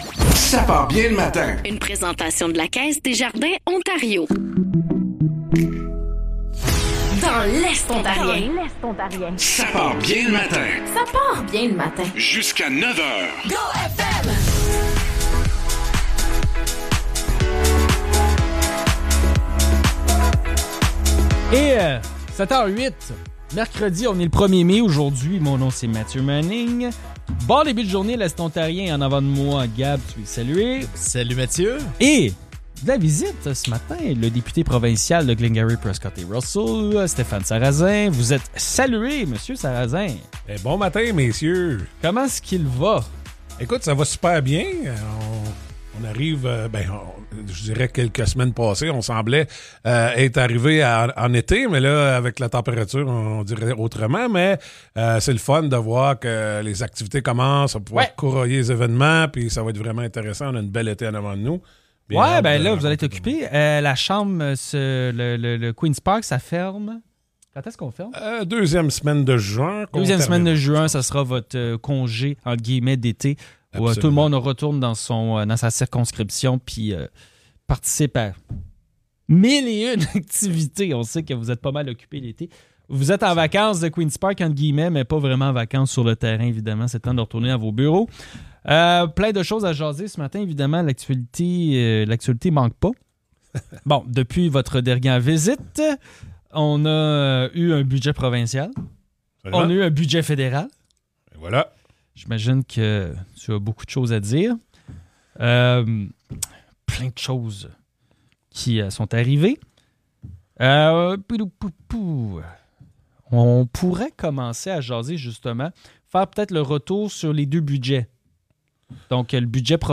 Le député provincial de Glengarry-Prescott-Russell, Stéphane Sarrazin, est venu passer une heure avec nous en studio.